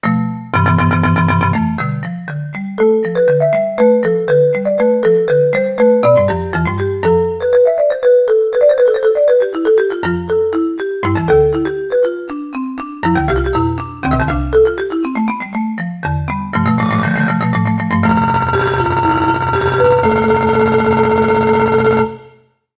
Xilófono. Breve pieza.
idiófono
xilófono
percusión
baqueta
madera